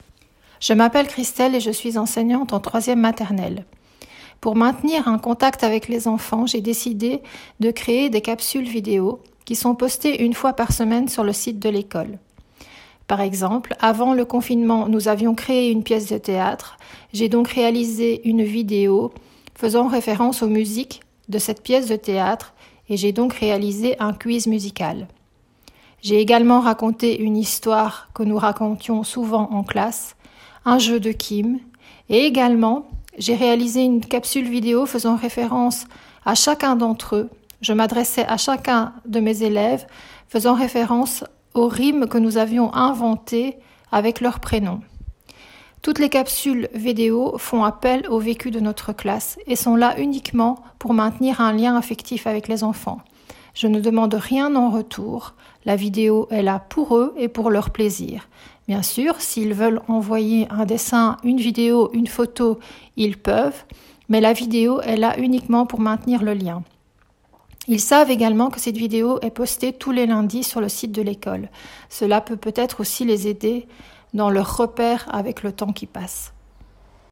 Des témoignages audio de professionnels de l’enseignement